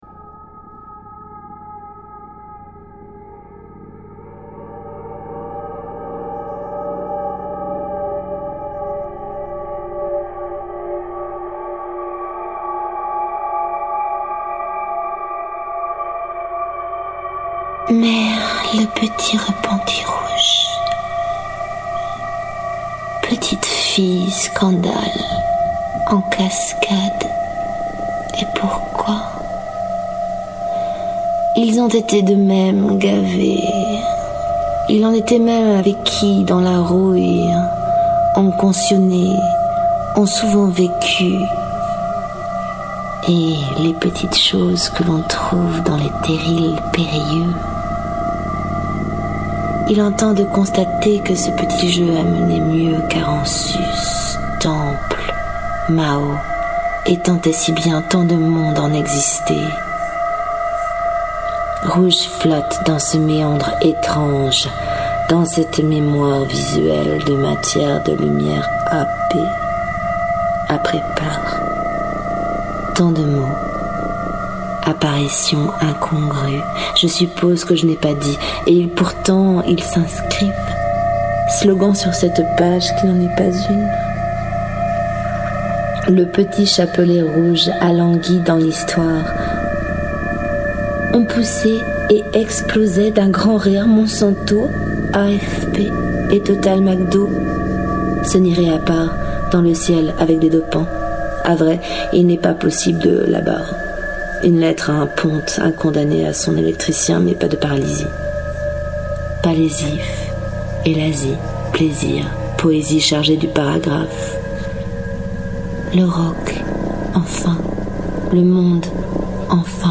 Mais le petit repentit rouge Lecture